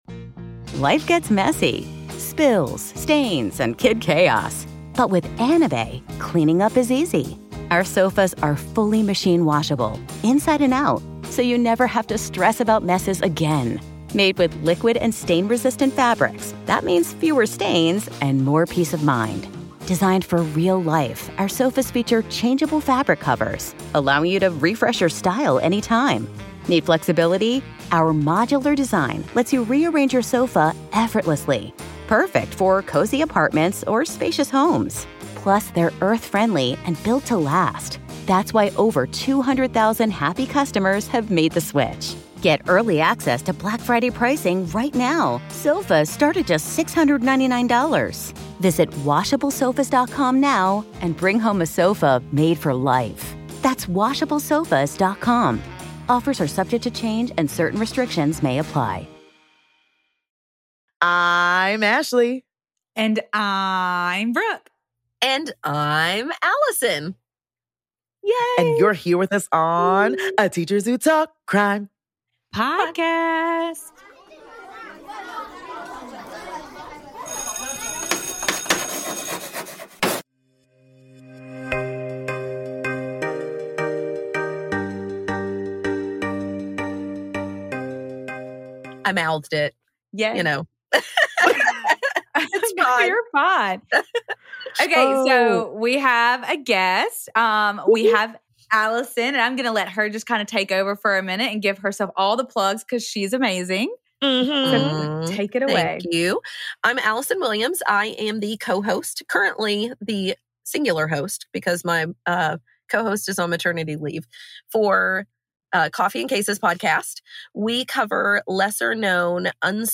Sound and YouTube editing by: Wayfare Recording Co.